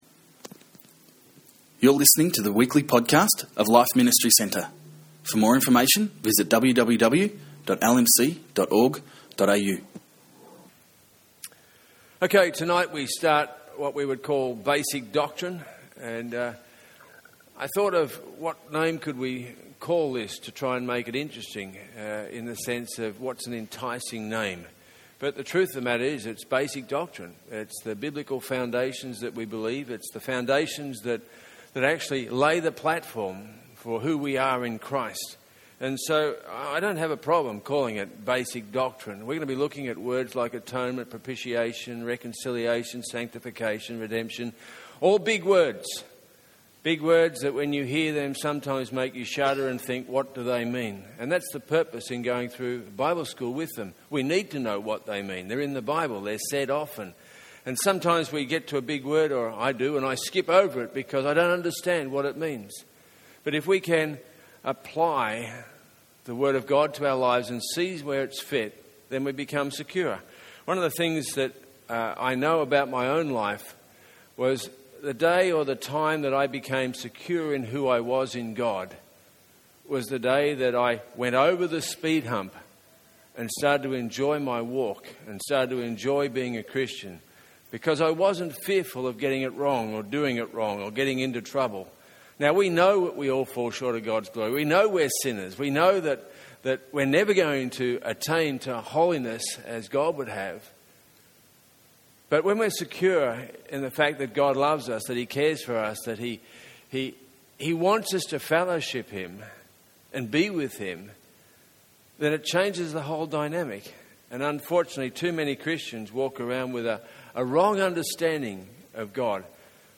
Biblical Doctrine at LMC Bible School, with the topic of The Atonement.